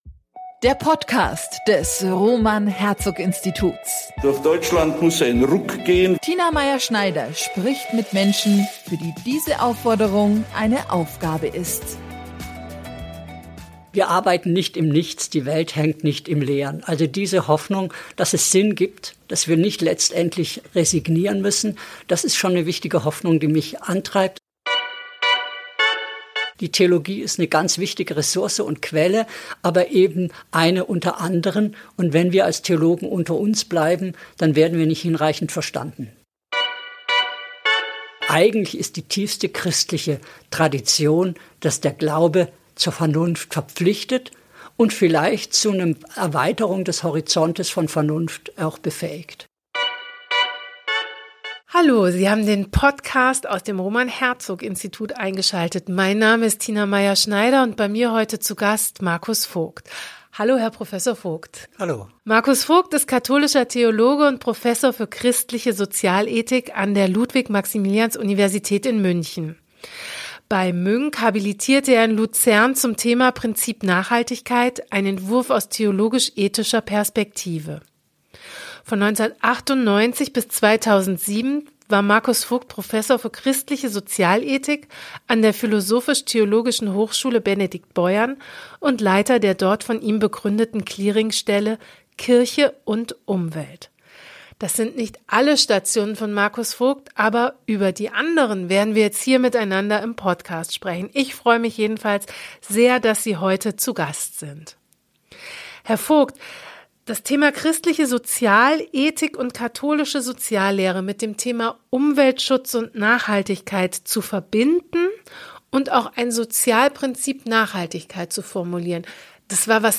Im Gespräch über seine Karriere betont er zwar wiederholt die glücklichen Fügungen, die ihn ‚zufällig‘ immer weitergebracht haben.